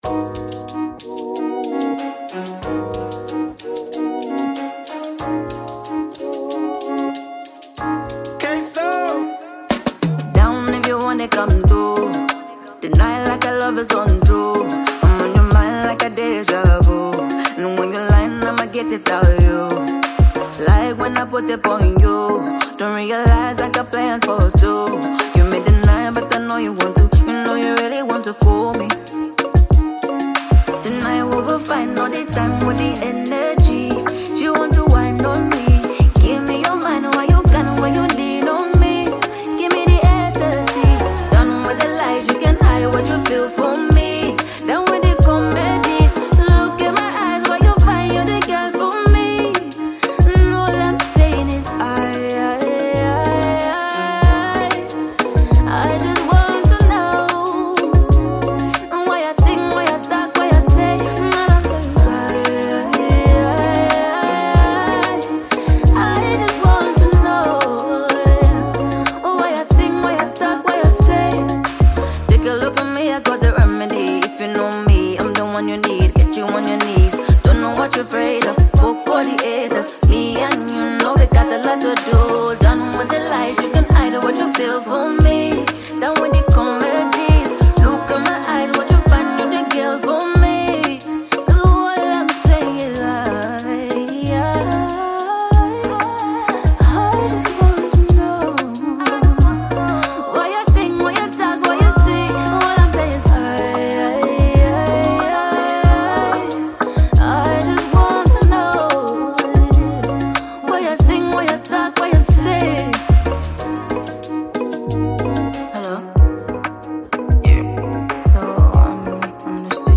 Ghanaian female vocalist and songwriter